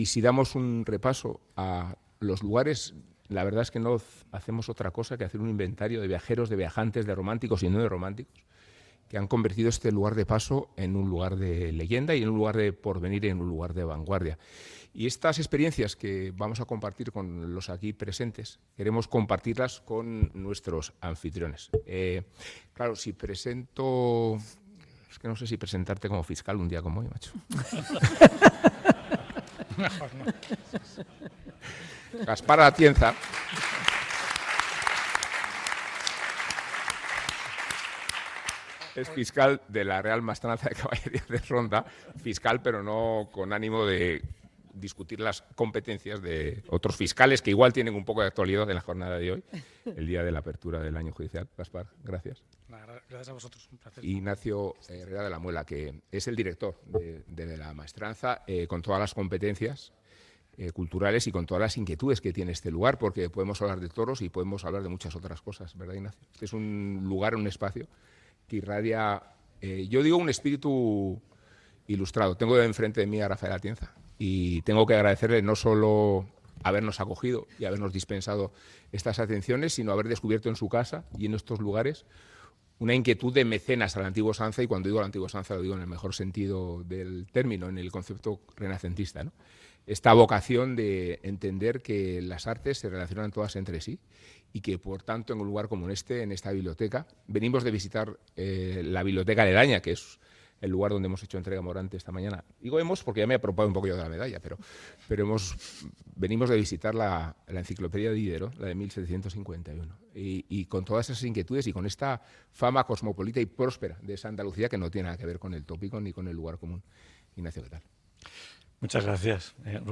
Programa fet des de la Real Maestranza de Caballería de Ronda.
Cultura